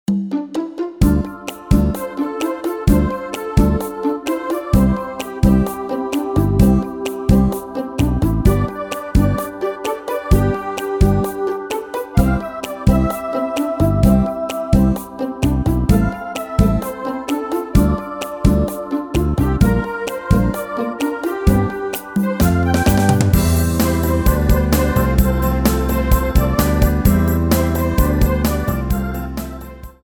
Rubrika: Hudba z filmů, TV, muzikály